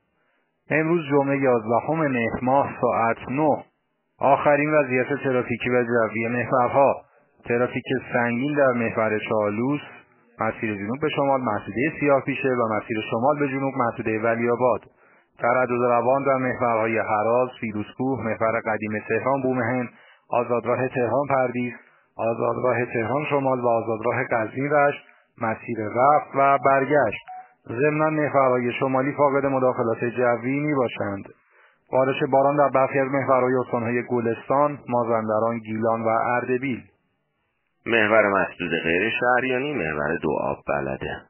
گزارش رادیو اینترنتی از آخرین وضعیت ترافیکی جاده‌ها ساعت ۹ یازدهم مهر؛